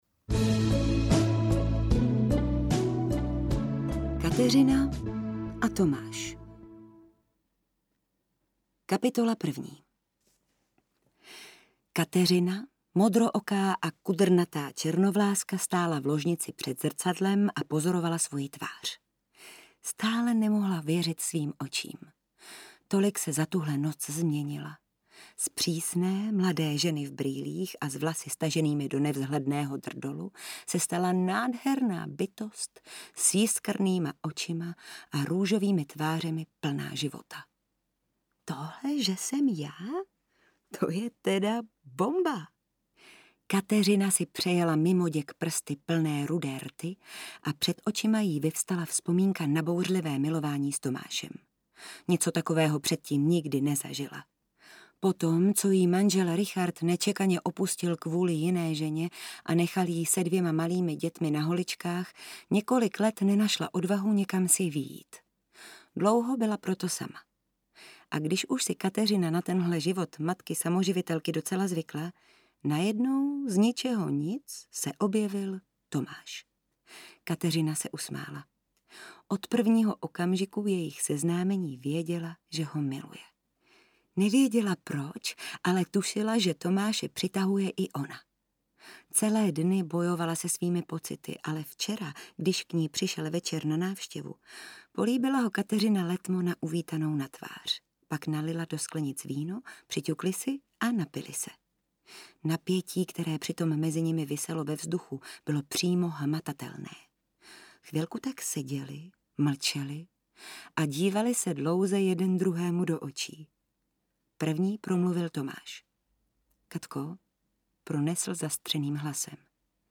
Život plný lásky audiokniha
Ukázka z knihy